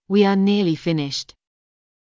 ｵｰﾙ ﾀﾞﾝ ﾌｫｰ ﾄｩﾃﾞｨ